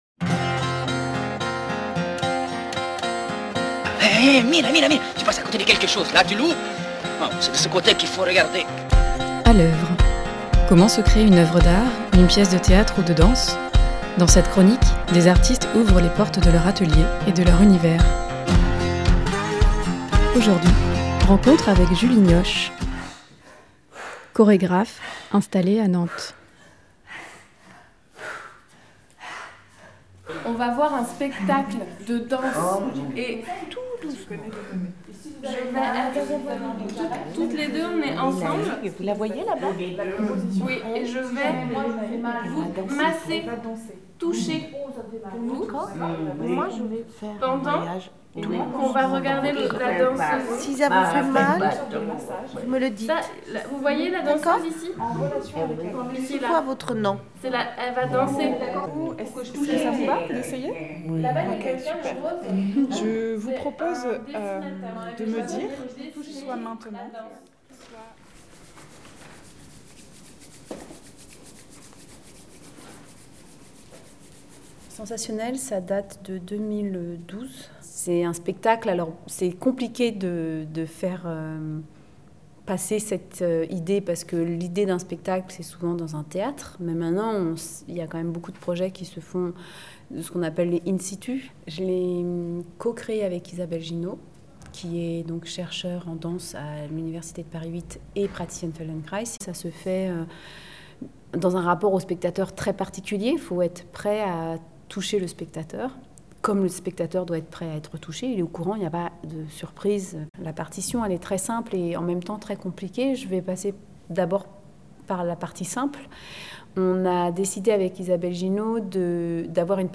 Comment se crée une œuvre d’art, une pièce de théâtre ou de danse ? Dans cette chronique, des artistes ouvrent les portes de leur atelier et de leur univers.